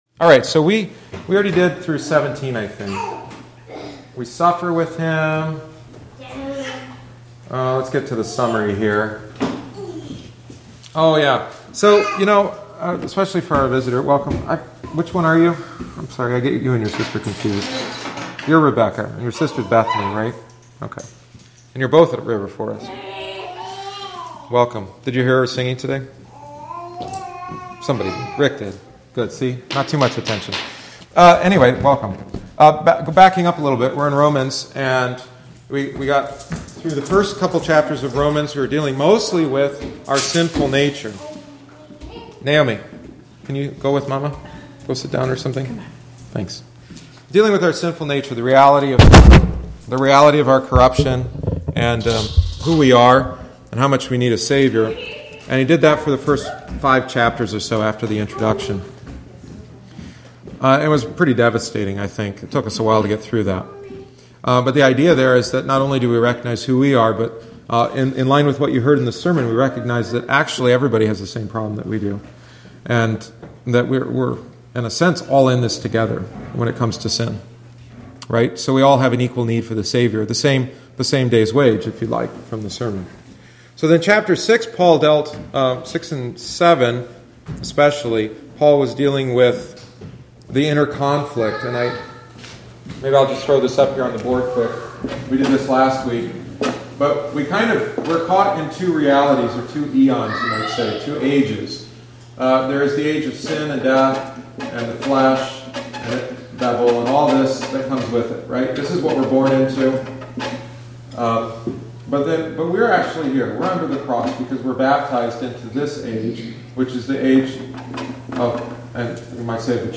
The following is the twentieth week’s lesson. This section talks about the suffering that Christians go through as they await Christ’s second coming. But it also speaks about how the Holy Spirit helps us pray for help.